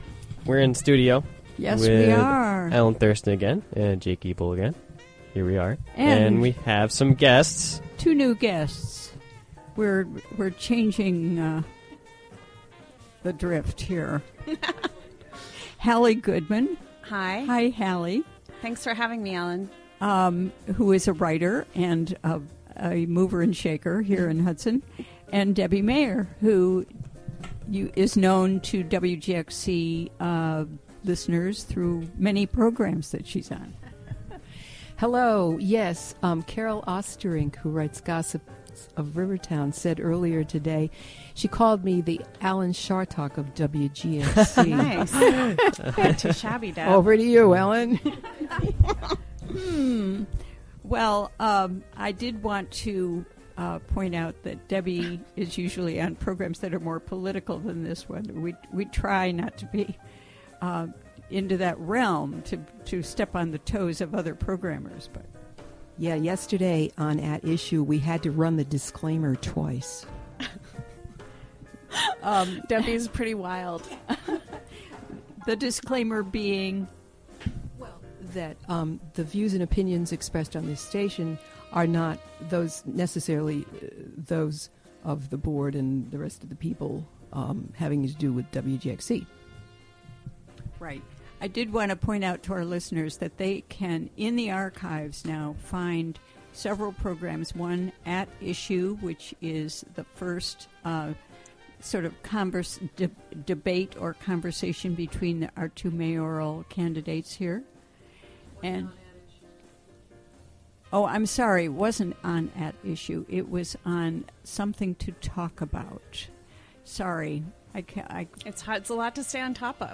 Interview recorded during the WGXC Afternoon Show.